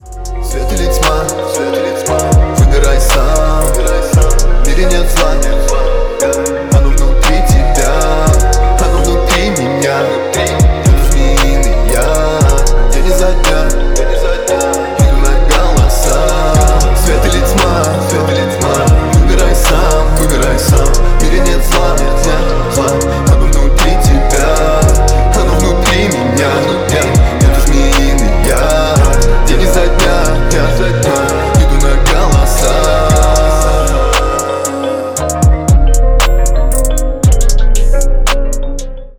мужской вокал
лирика
спокойные